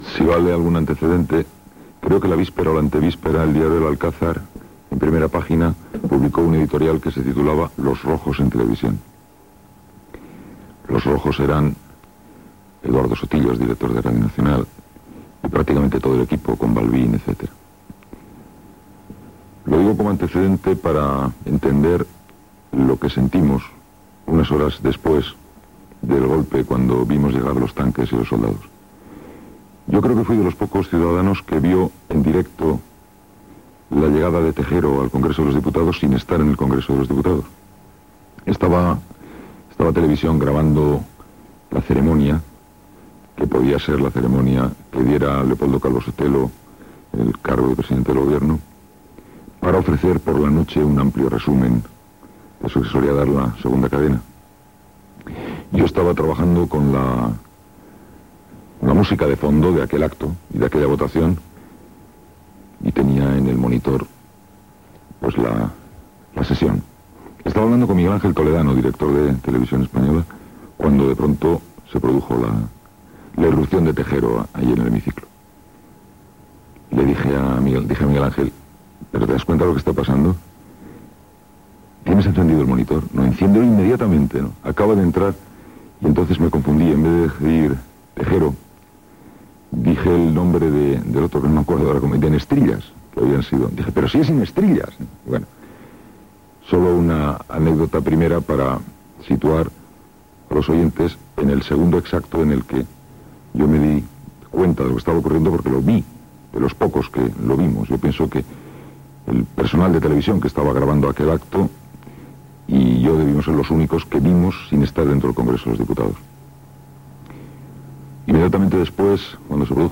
Programa presentat per Joan Manuel Serrat